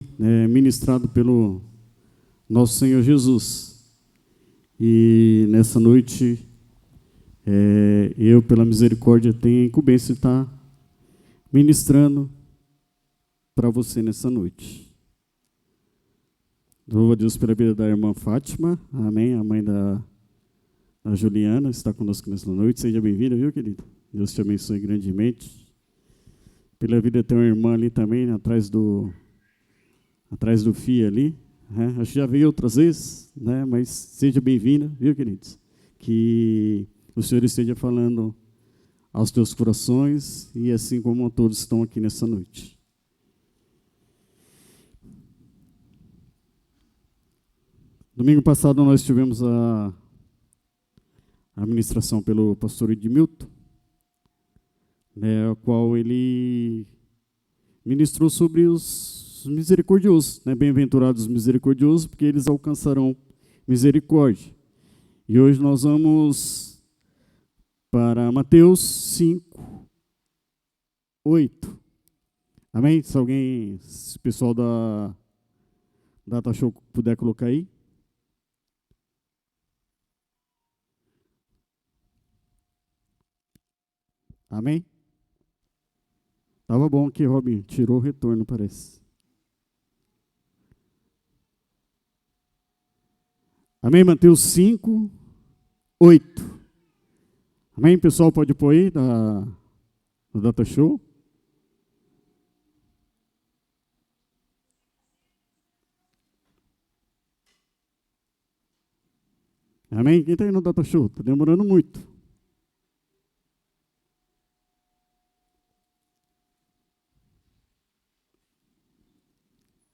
sermao-mateus-5-8.mp3